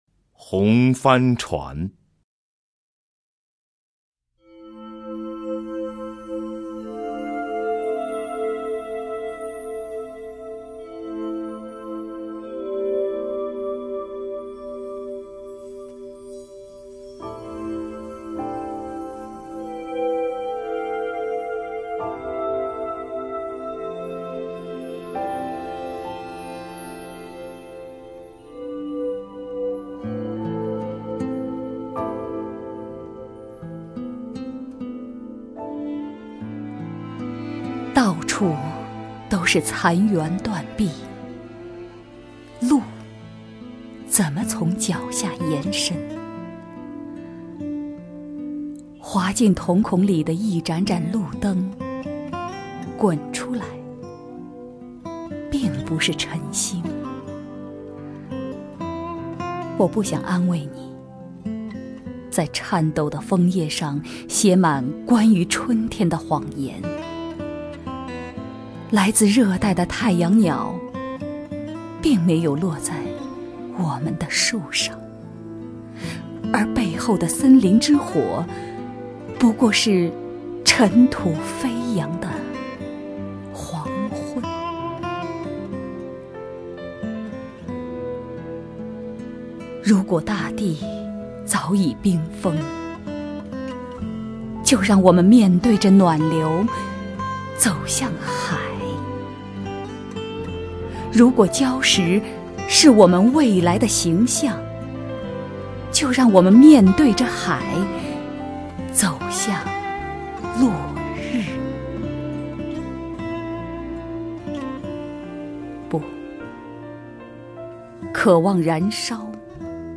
首页 视听 名家朗诵欣赏 狄菲菲
狄菲菲朗诵：《红帆船》(北岛)
HongFanChuan_BeiDao(DiFeiFei).mp3